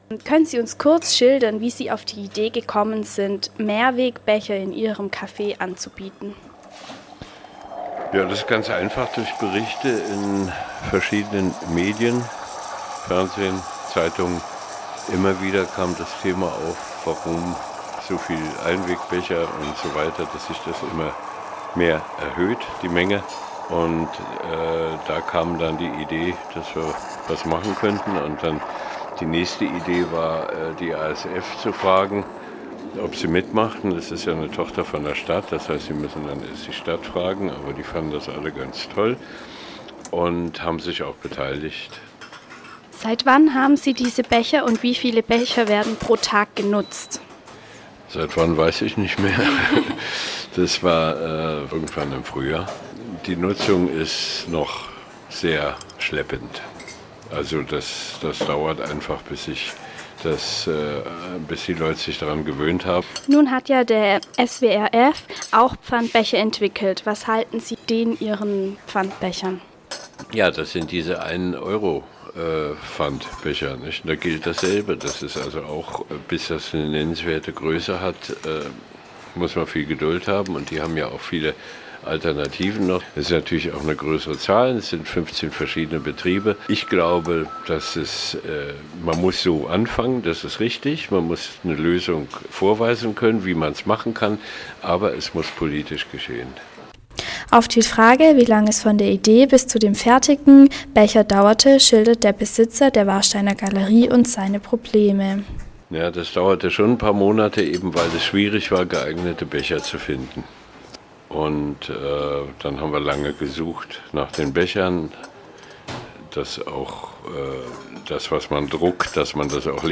Dieser Eintrag wurde veröffentlicht unter Interview Freiburg Gesundheit Umwelt Podcast-Archiv der PH-Freiburg und verschlagwortet mit Ökologie & Nachhaltigkeit Praxisseminar Medienpädagogik deutsch am von